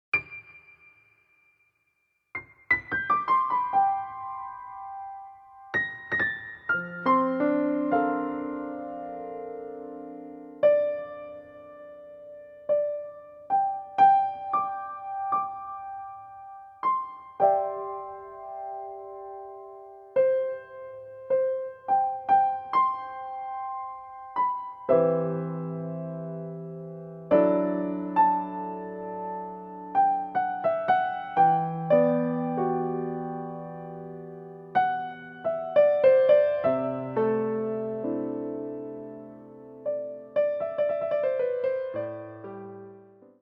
A collection of original piano solos